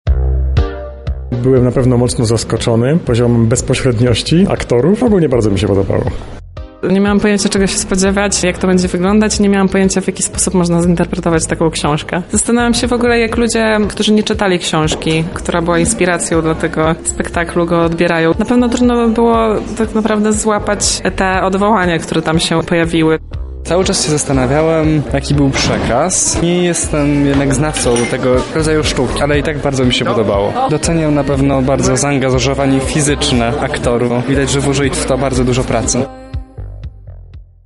Nastroje po przedstawieniu sprawdzała nasza reporterka